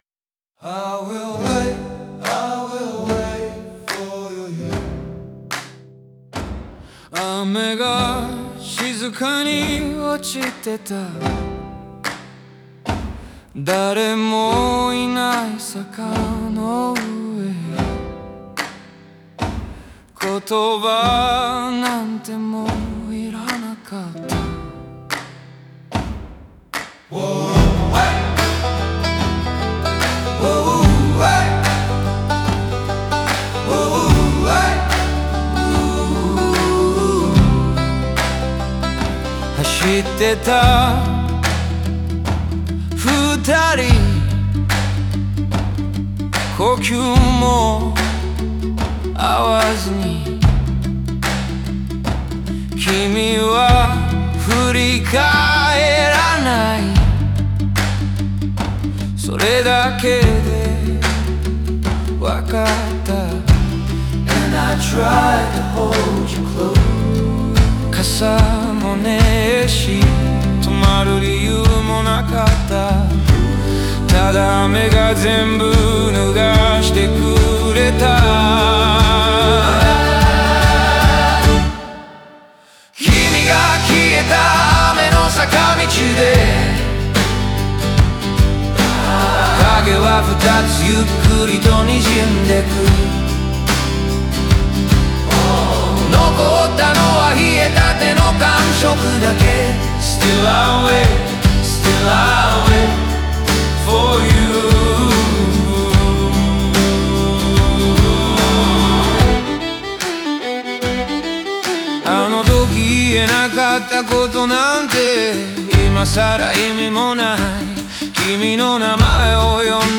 フォークロックの乾いた響きと、英語のハーモニーが切なさと祈りを同時に奏でる。